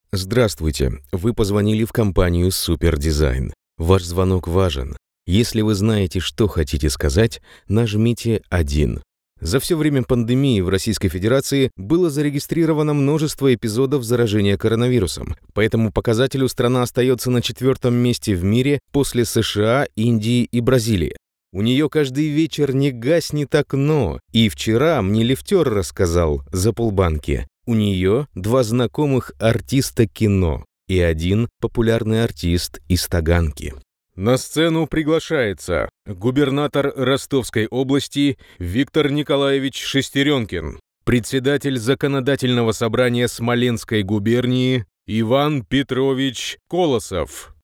Домашняя акустическая кабина.
Тракт: TLM 102, M-Audio FT C400